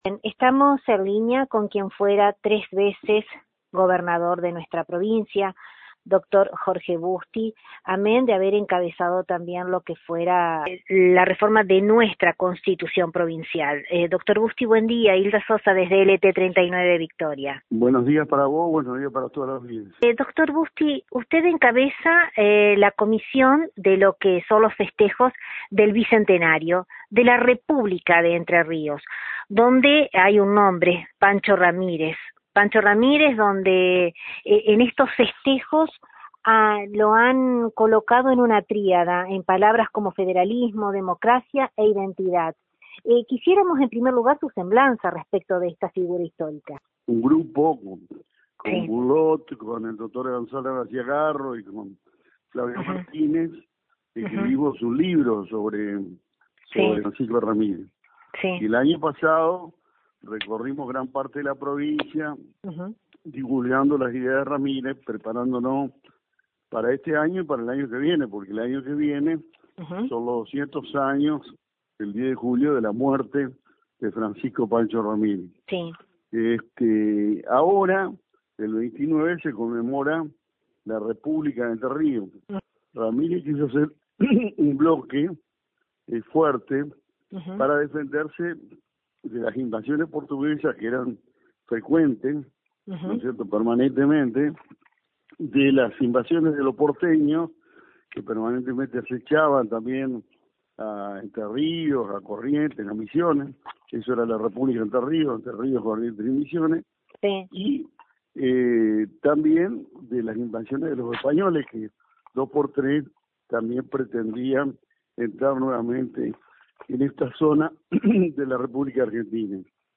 Desde LT39 NOTICIAS, dialogamos con el ex gobernador concordiense; con quien pudimos traer al presente, la figura relevante del ex caudillo Pancho Ramírez, siembra fiel de grandes ideas instaladas y cosechadas a lo largo de nuestro transitar entrerriano; como así también, obtuvimos su apreciación de un presente complicado, que impera en nuestra realidad; donde no hizo mella al hacer un análisis político, atrevesado por nombres como el de Mauricio Macri y Eduardo Duhalde.